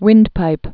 (wĭndpīp)